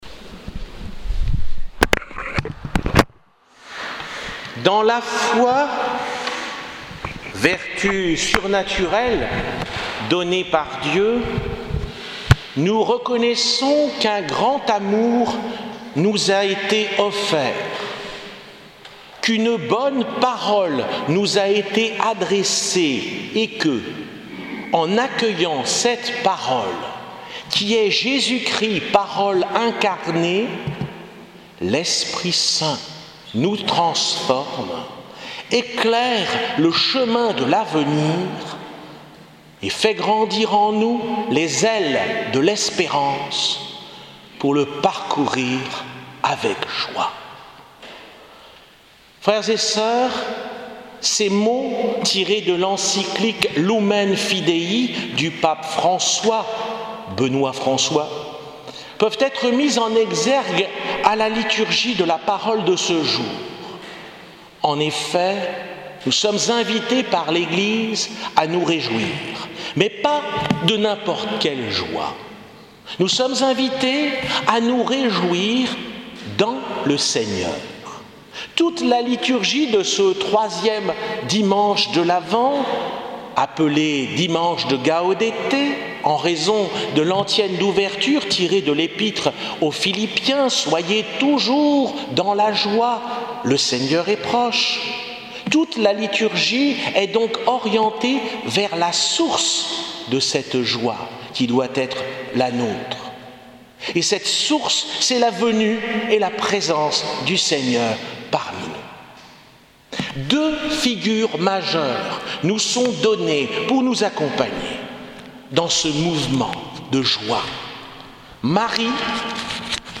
Homélie du dimanche 17 décembre 2017 | Les Amis du Broussey